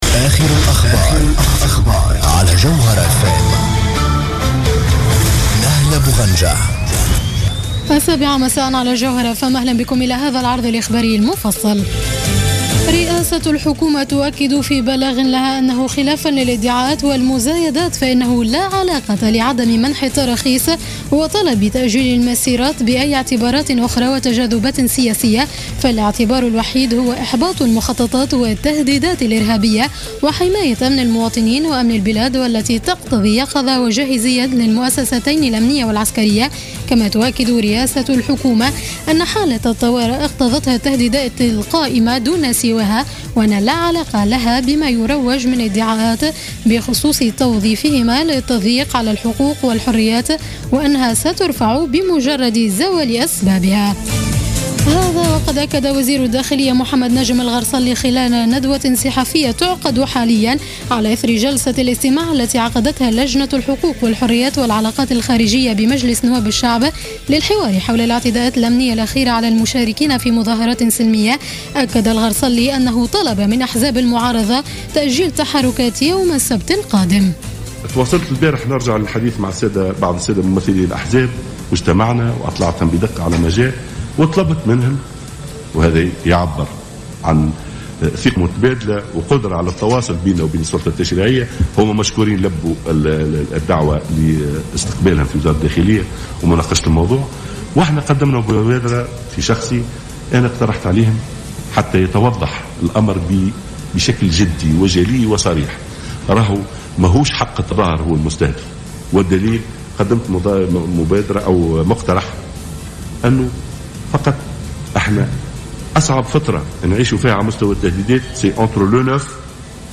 نشرة أخبار السابعة مساء ليوم الخميس 10 سبتمبر 2015